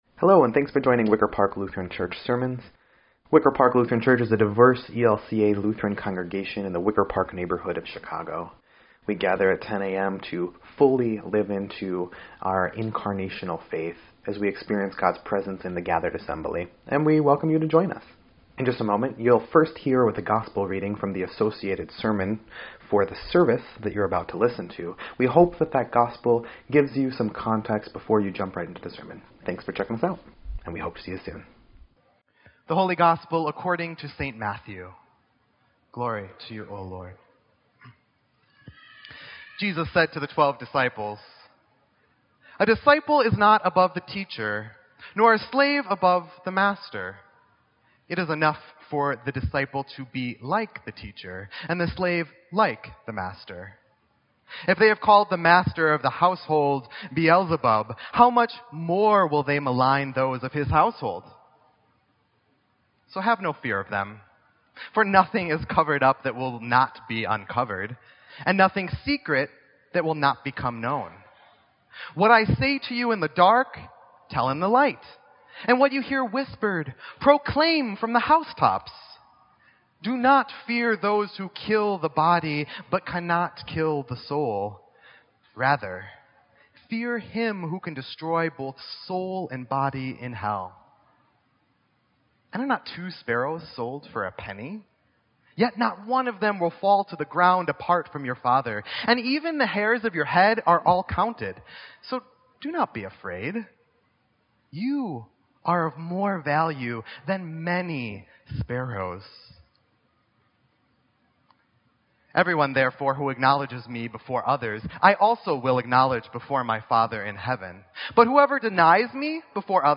Sermon_6_25_17_EDIT.mp3